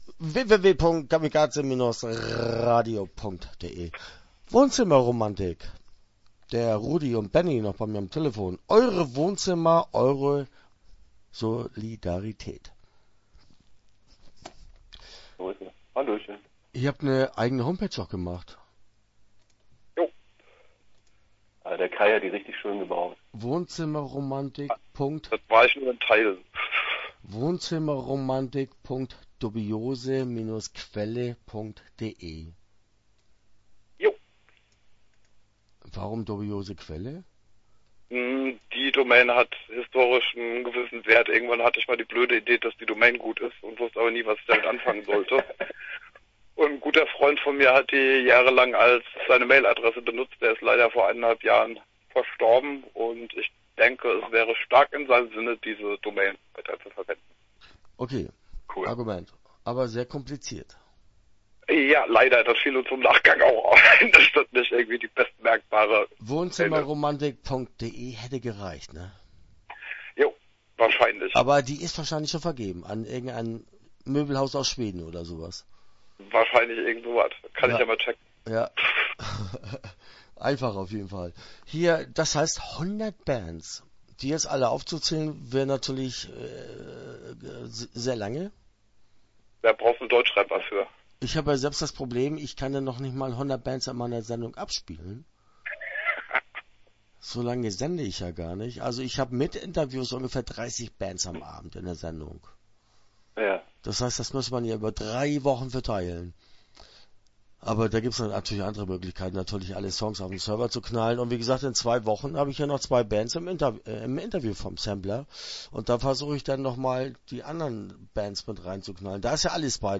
Wohnzimmer Romantik - Interview Teil 1 (10:46)